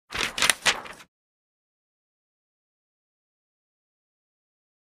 paper.ogg